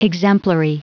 Prononciation du mot exemplary en anglais (fichier audio)
Prononciation du mot : exemplary